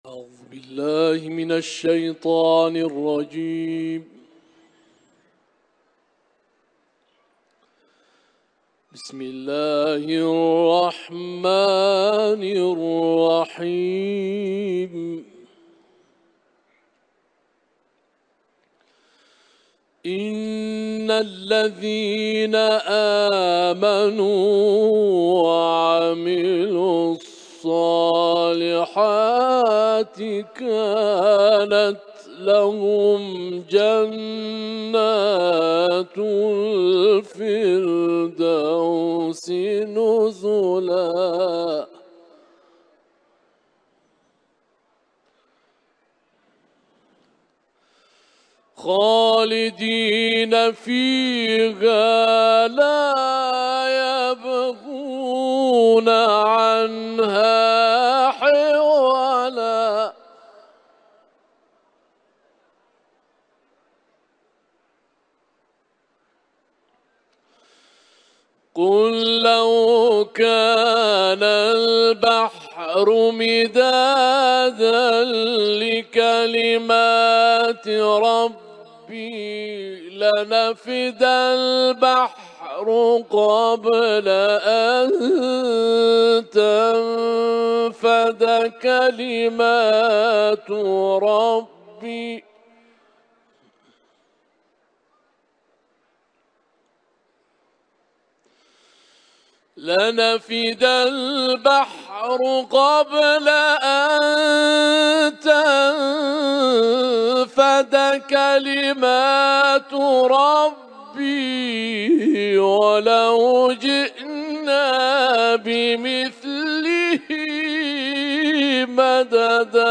IQNA – Uluslararası İranlı kâri Kehf suresinin 107-110. ayetlerini bunun yanısıra Tîn suresinden ayetler tilavet etti.